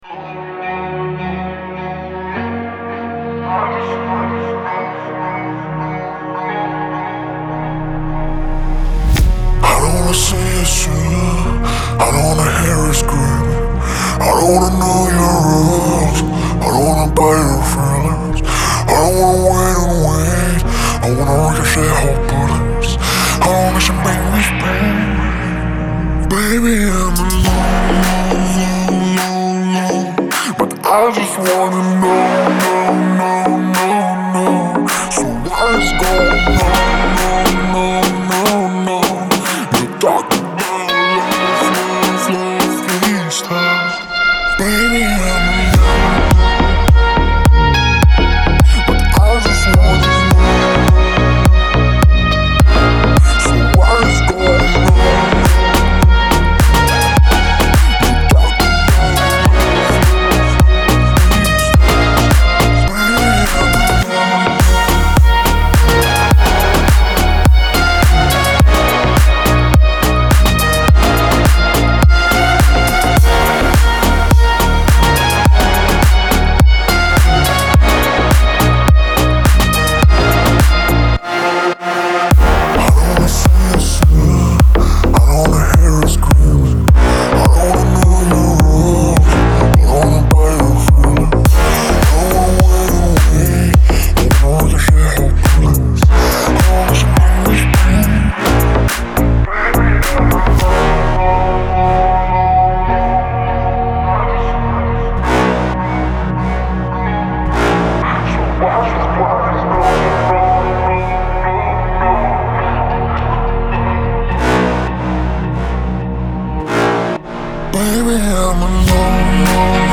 это захватывающая песня в жанре хип-хоп